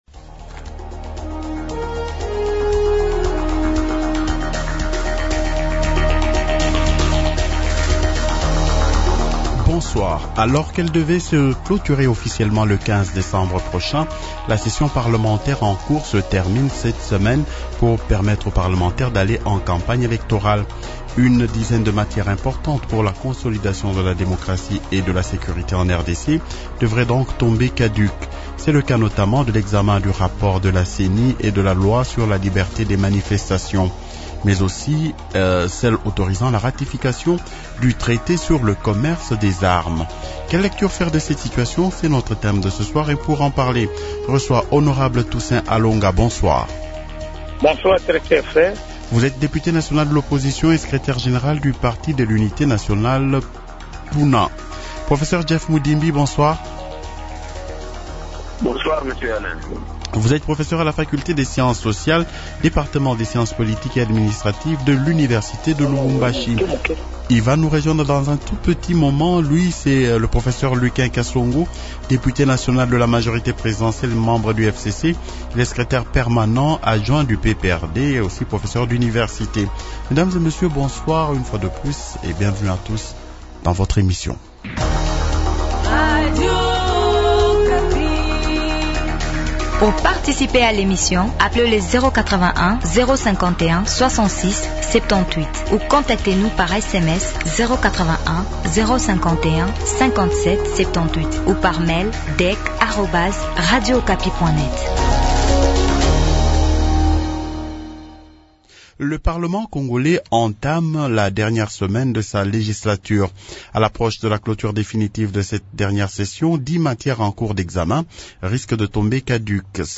Mais aussi celle autorisant la ratification du traité sur le commerce des armes -Quelle lecture faire de cette situation ? les Invites -Lucain Kasongo, Député national de la majorité présidentielle et membre du FCC.
-Toussaint Alonga, Député national de l’opposition et secrétaire général du Parti de l’Unité Nationale (PUNA).